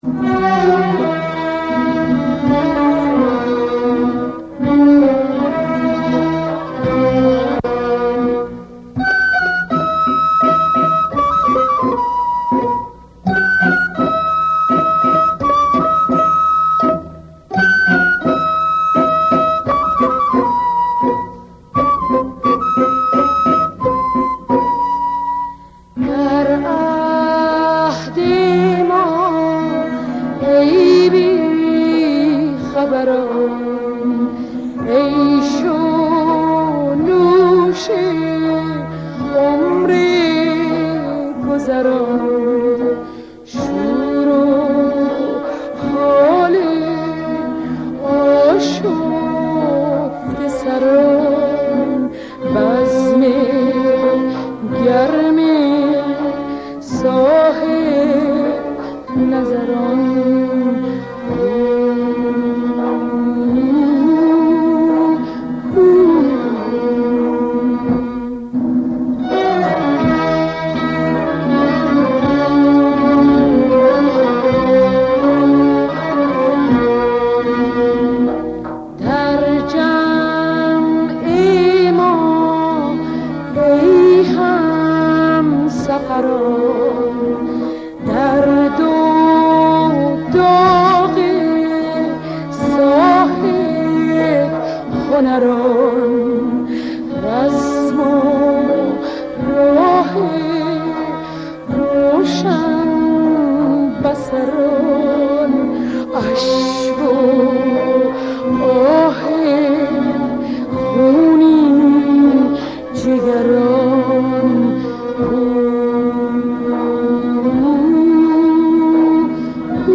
پاپ